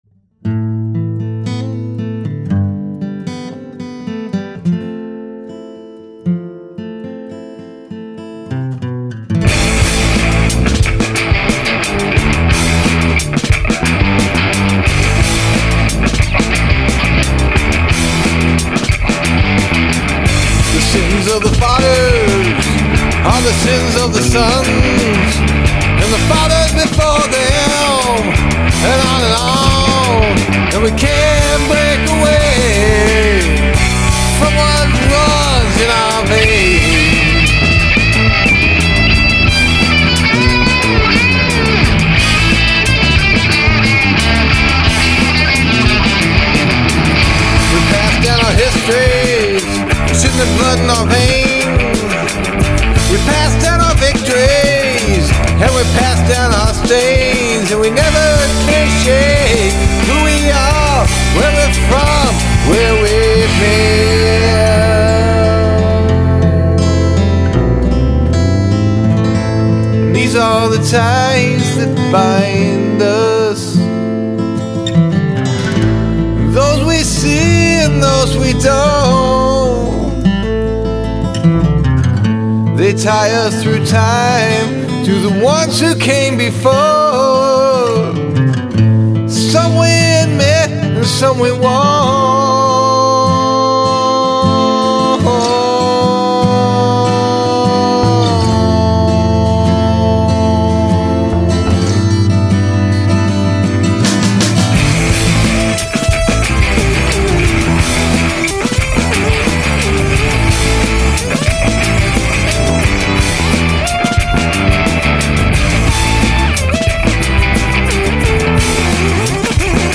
Must include three different tempos
Sweet ELP synth & period wah.
Backing vox, shrill (but brief).